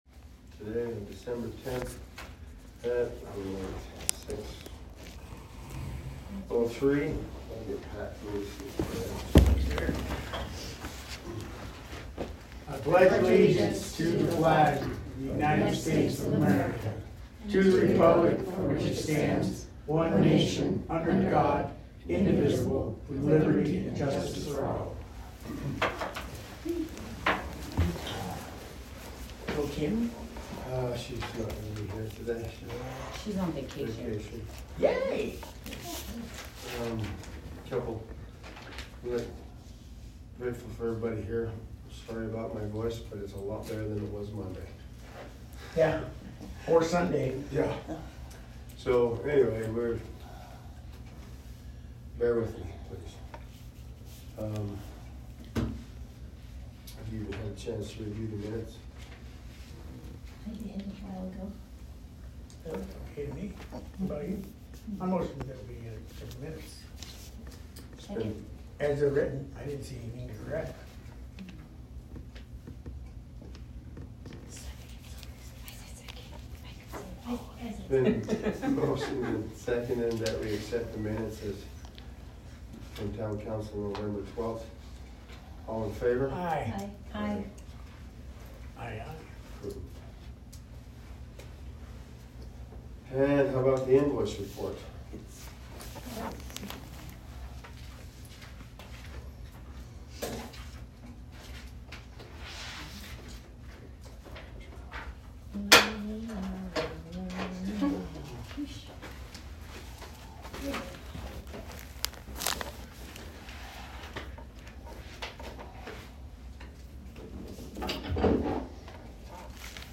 Download Dec. 10th town council.m4a (opens in new window)